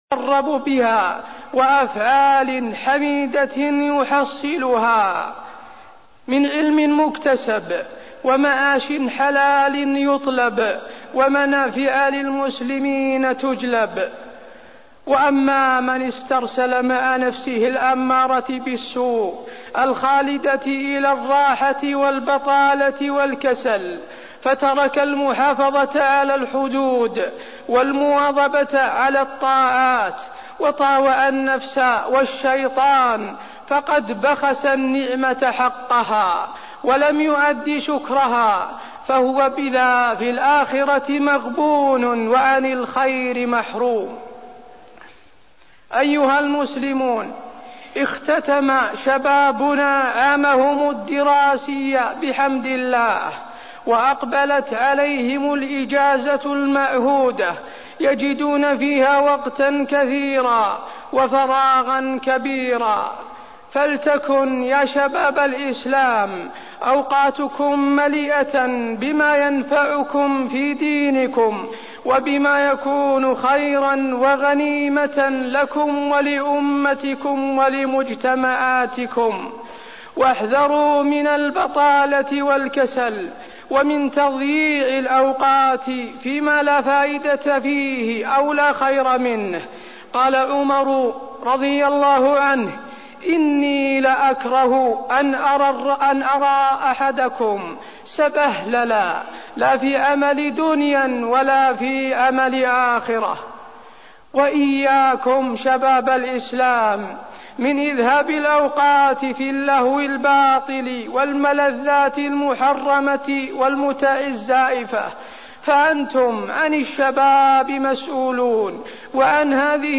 تاريخ النشر ٢ ربيع الأول ١٤١٩ هـ المكان: المسجد النبوي الشيخ: فضيلة الشيخ د. حسين بن عبدالعزيز آل الشيخ فضيلة الشيخ د. حسين بن عبدالعزيز آل الشيخ الوقت